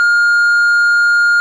answering_machine.wav